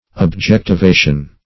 Objectivation \Ob*jec`ti*va"tion\